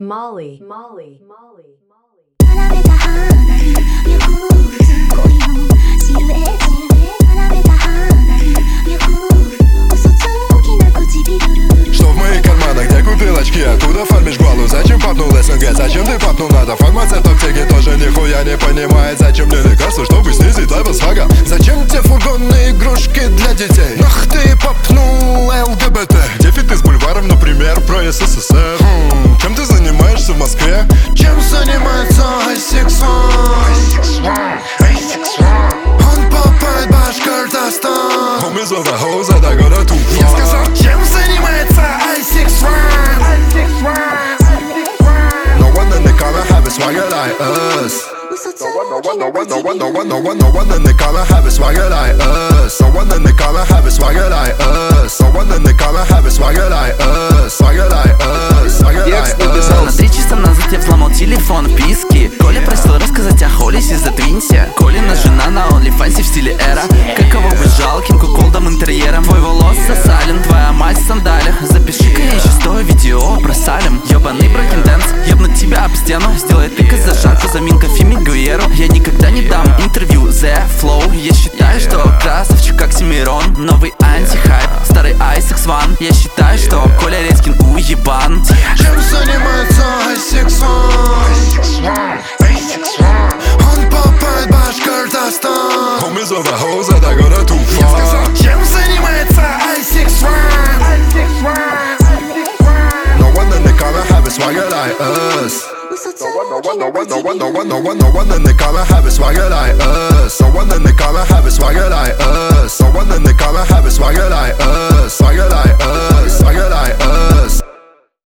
Рок музыка 2025